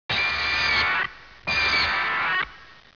Gigan has a distinctive, rasping cry and uses powerful blows from its hooklike claws to demolish any manmade structure blocking its path.